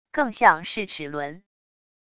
更像是齿轮wav音频